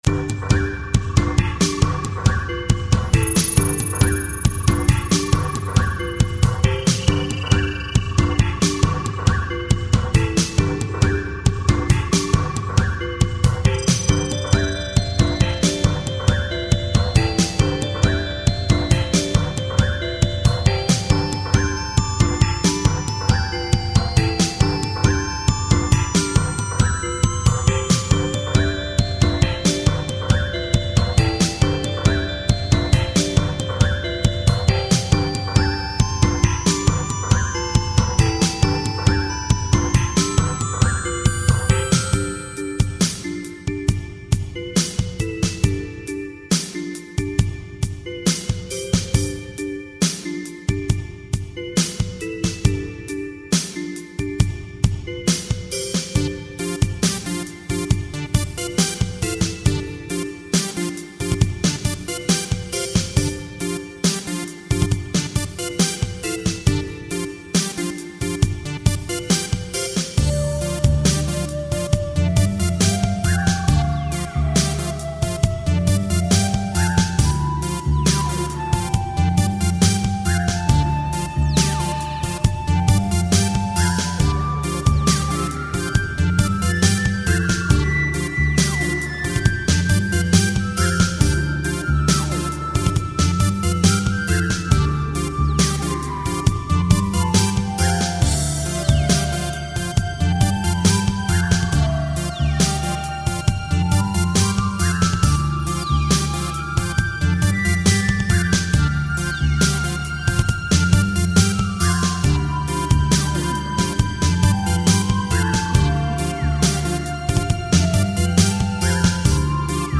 File under: New Electronica